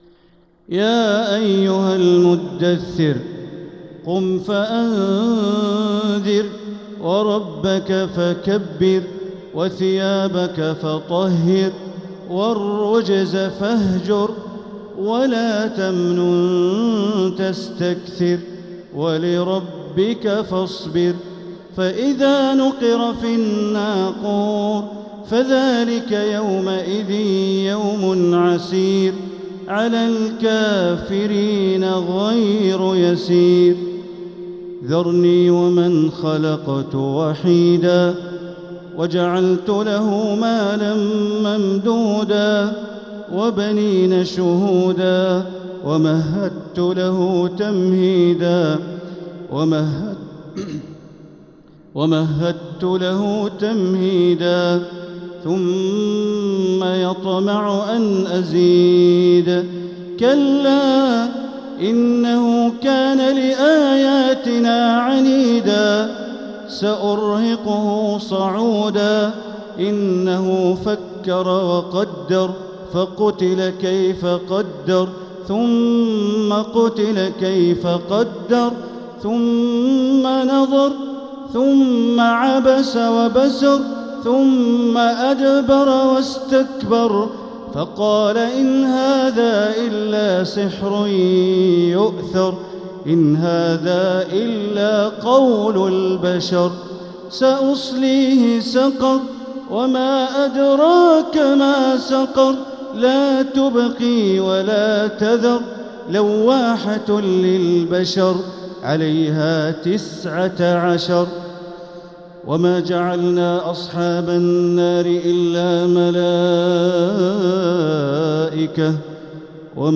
سورة المدثر | مصحف تراويح الحرم المكي عام 1446هـ > مصحف تراويح الحرم المكي عام 1446هـ > المصحف - تلاوات الحرمين